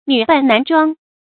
女扮男裝 注音： ㄋㄩˇ ㄅㄢˋ ㄣㄢˊ ㄓㄨㄤ 讀音讀法： 意思解釋： 女子穿上男裝，打扮成男子的模樣 出處典故： 清·李汝珍《鏡花緣》第66回：「及至此時，才想起他原是 女扮男裝 。」